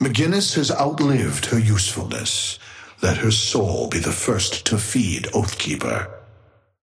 Amber Hand voice line - McGinnis has outlived her usefulness.
Patron_male_ally_ghost_oathkeeper_5a_vs_mcg_start_01.mp3